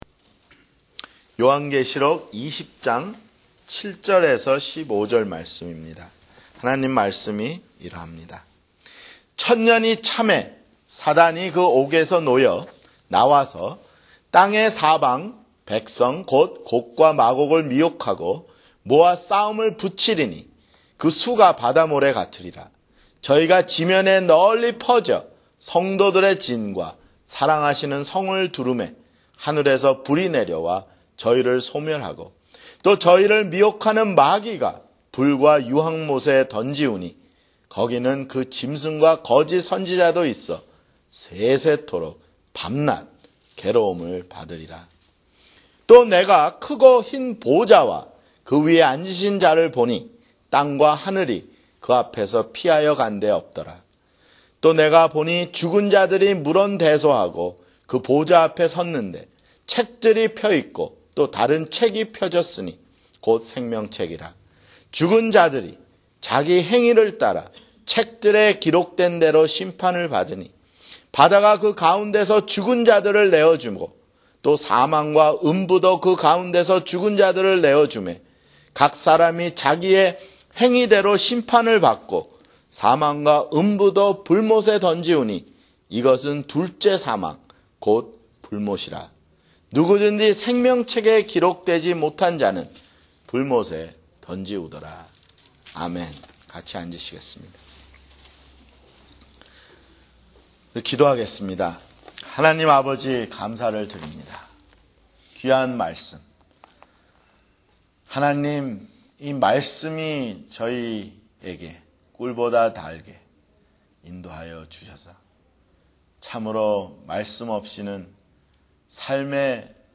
[주일설교] 요한계시록 (79)